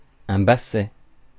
Ääntäminen
Ääntäminen France: IPA: /ba.sɛ/ Haettu sana löytyi näillä lähdekielillä: ranska Käännöksiä ei löytynyt valitulle kohdekielelle.